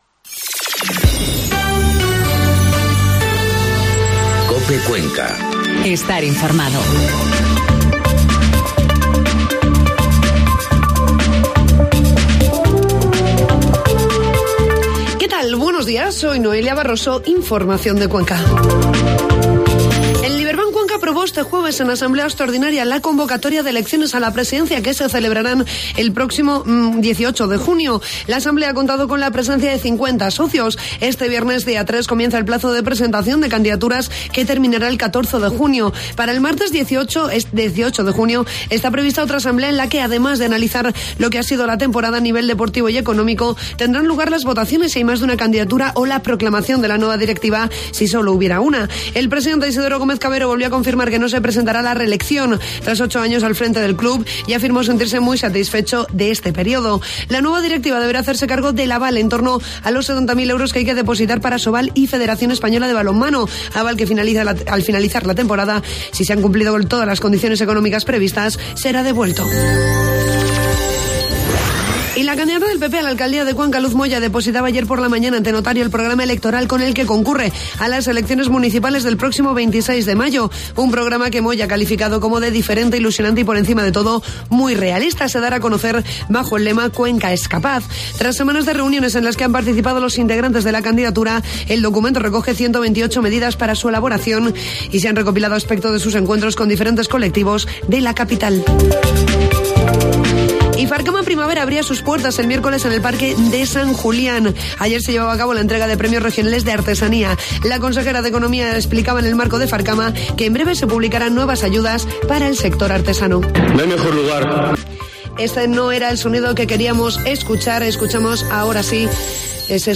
Informativo matinal COPE Cuenca 3 de mayo